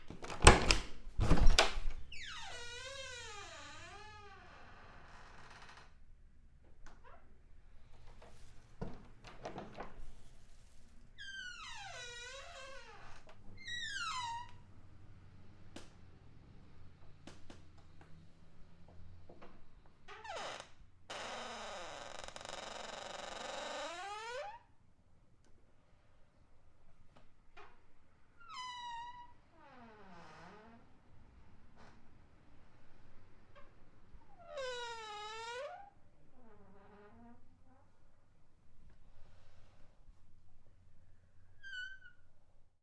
随机的 " 木质的门 嘎吱作响的公寓 打开关闭 小的温柔的嘎吱声 房间的 BG
描述：门木多节公寓吱吱作响开放关闭小温柔吱吱作响宽敞的bg.flac
Tag: 打开 关闭 温柔的 摇摇欲坠的 吱吱作响 公寓 粗糙